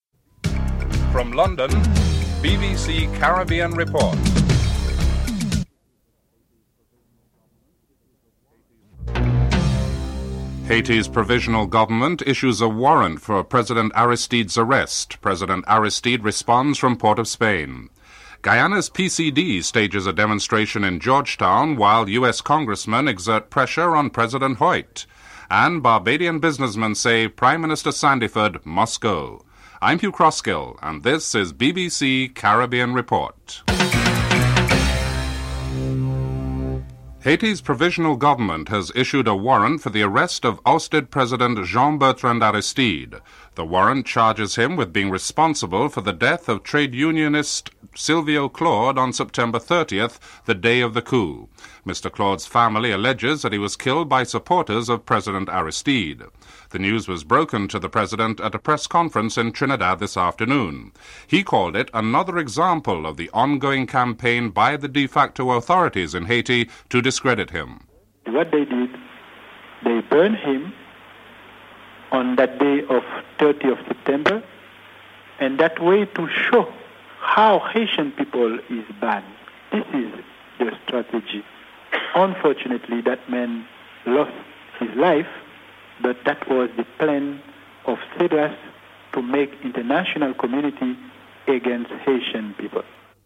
1. Headlines (00:00-00:35)
Telephone interview with Democratic Congressman, Peter Kostmayer (01:43-07:25)